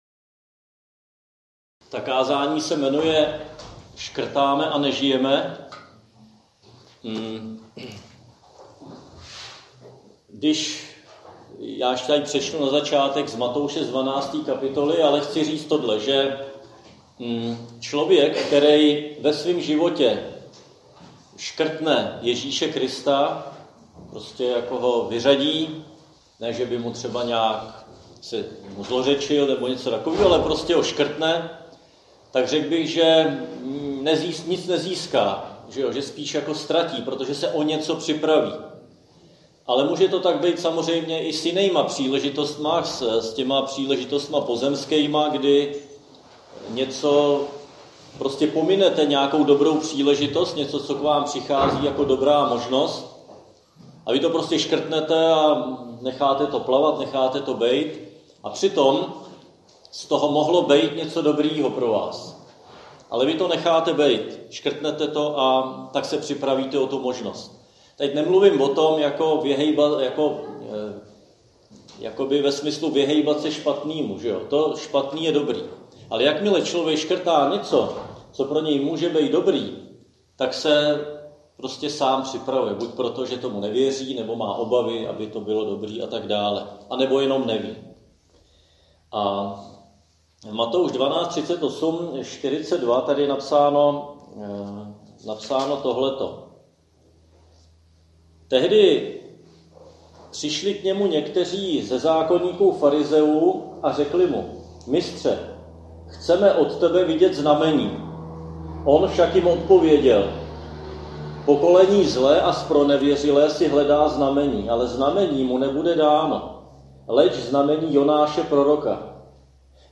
Křesťanské společenství Jičín - Kázání 29.5.2022